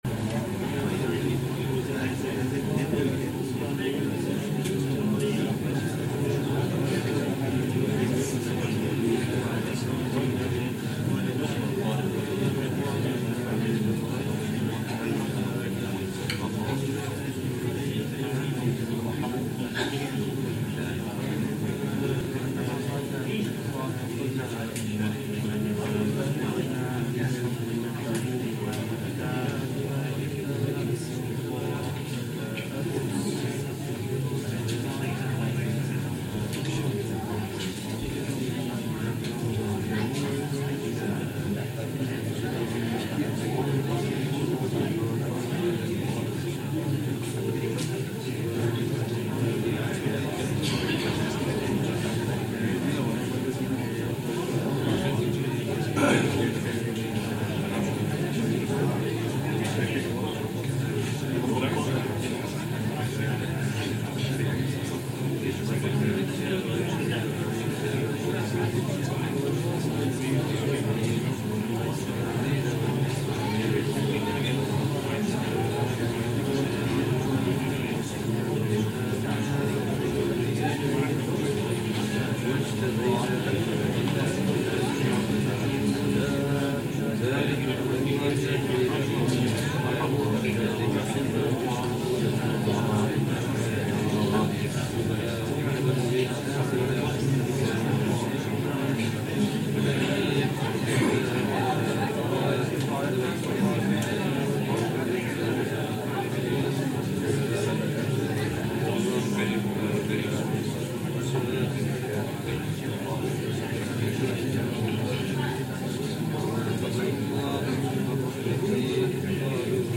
Masjid at-Taqwa, Bradford BD7